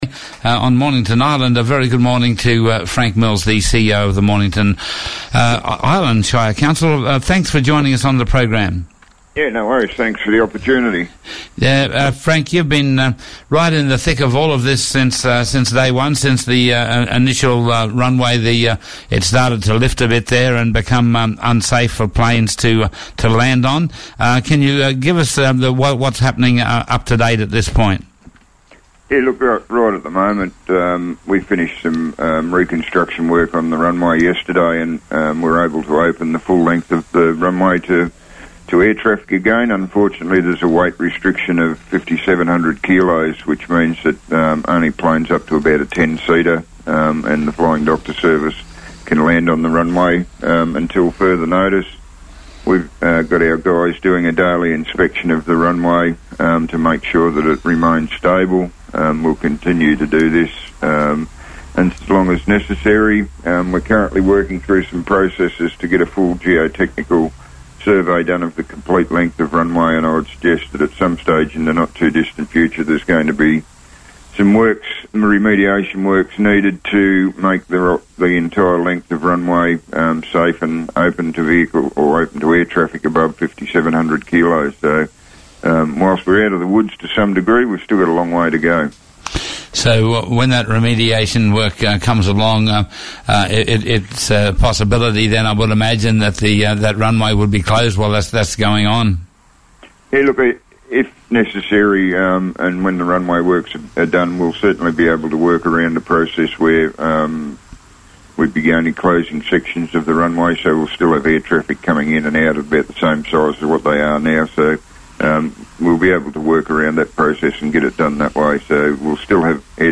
Gulf & Cape Leaders spoke with Black Star Radio on the re-opening of the Islands Airport. The airport was closed on 17 December 2015 and re-opened last week Friday with weight restrictions in place.